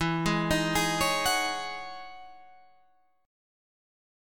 EM13 Chord